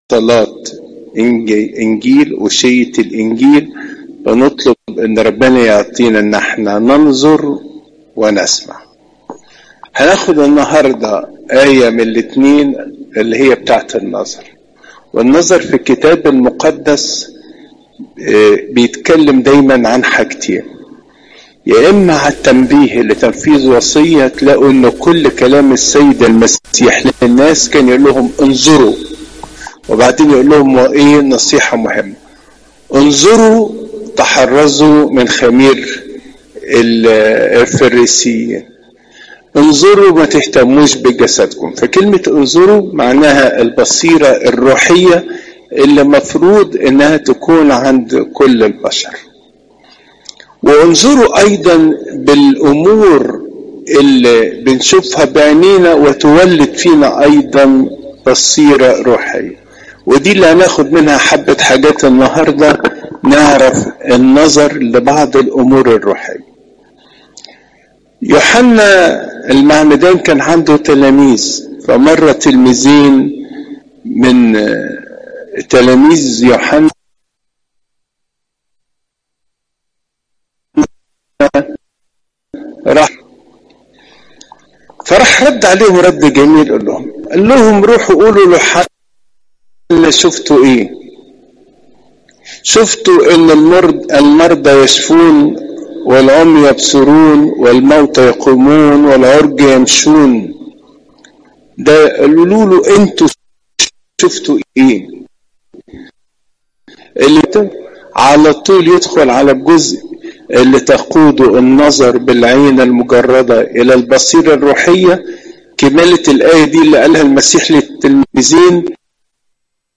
عظات قداسات الكنيسة